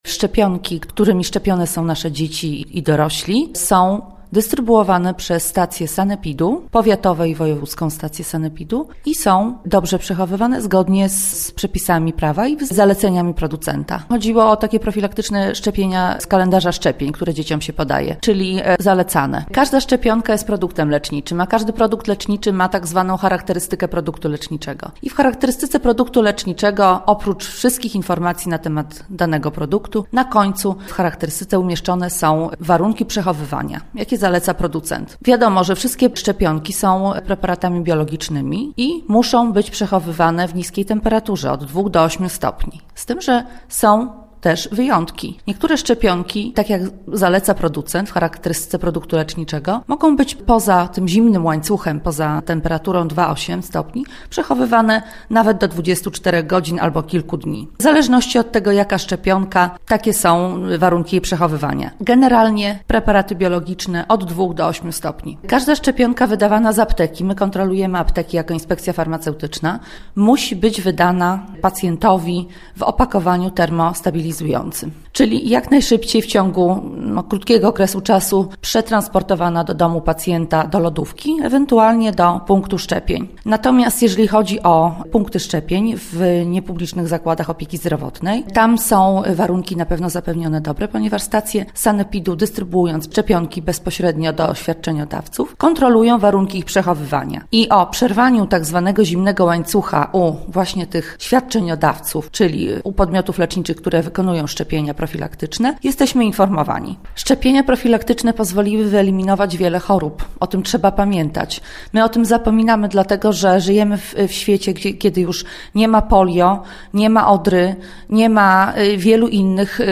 Lubelski Wojewódzki Inspektor Sanitarny Izabela Mahorowska-Kiciak – wyjaśnia jakie są procedury przy przechowywaniu szczepionek.